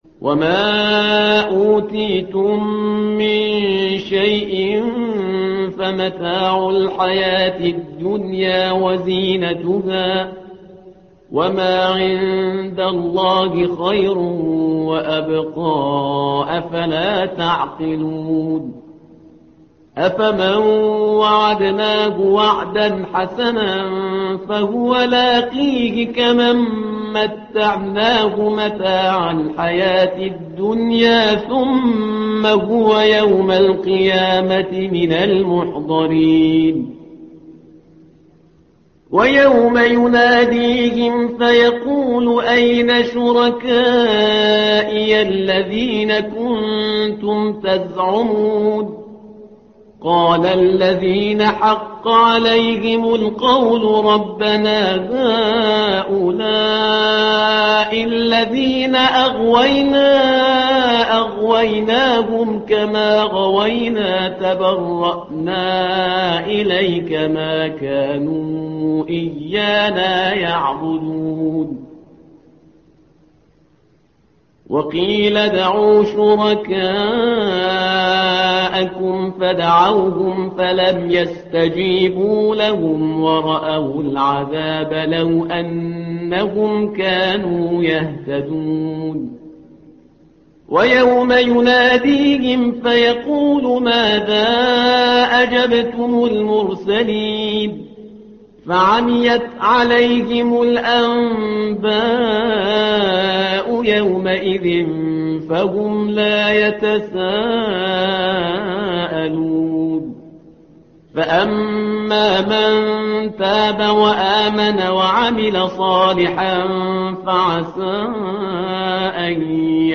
الصفحة رقم 393 / القارئ